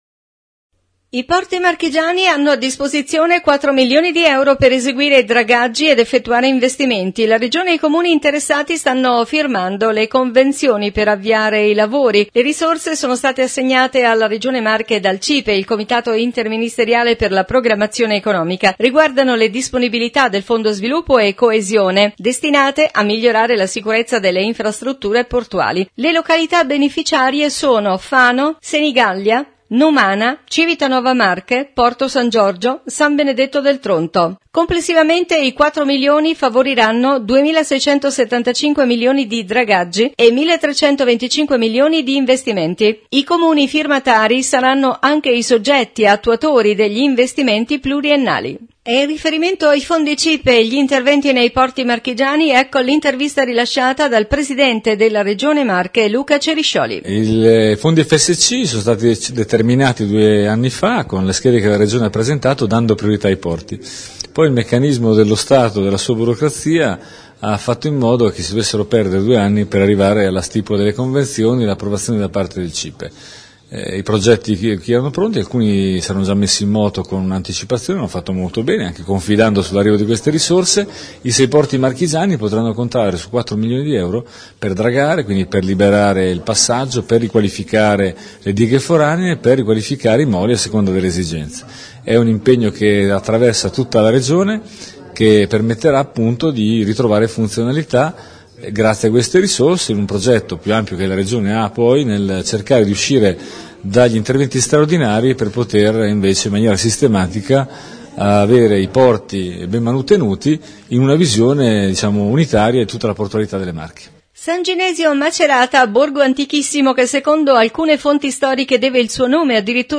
New Radio Star | Notizie Regione…Fondi Cipe: gli interventi nei porti marchigiani Intervista Luca Ceriscioli – Presidente Regione Marche